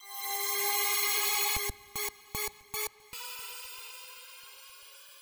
$CRIM RISER.wav